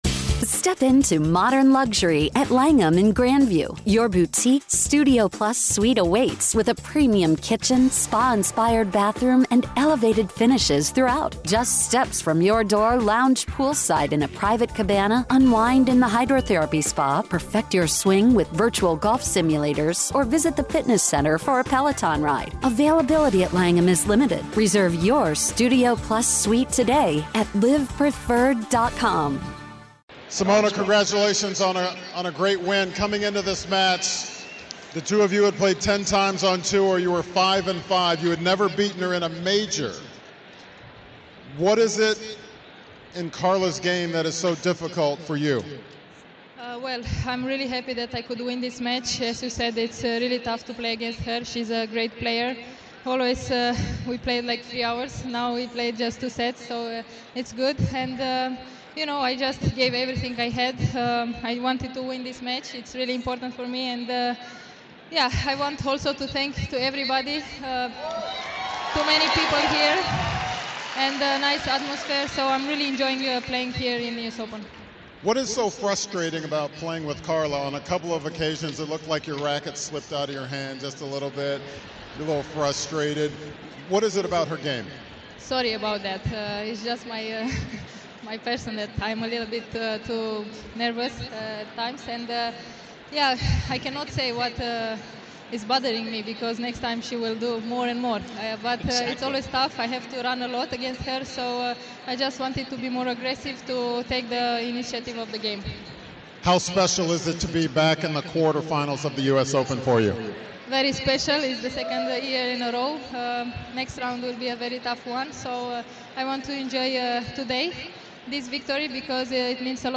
Simona Halep talks about her victory over Carla Suárez Navarro, 6-2, 7-5.